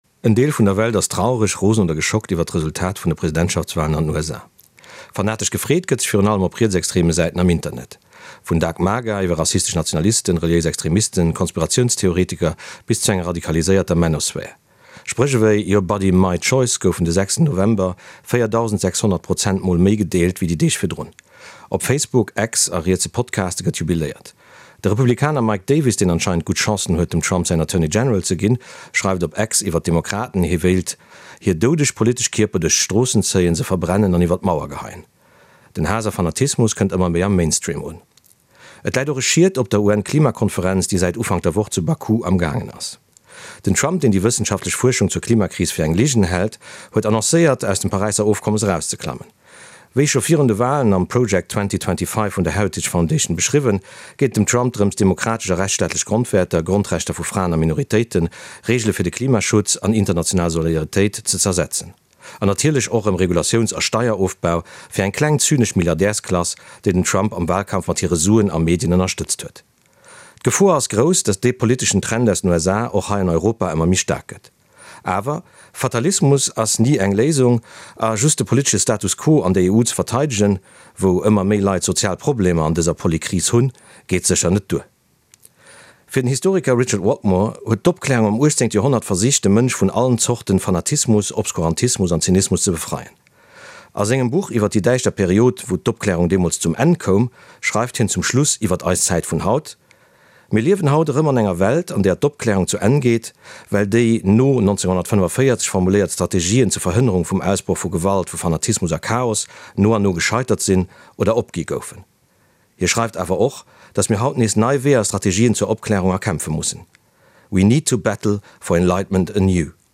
1 Bruit vert luxuriant | Plongez instantanément dans un sommeil profond 2:06:19
Un bruit vert luxuriant mélangé à une ambiance brune apaisante pour vous aider à vous endormir sans effort.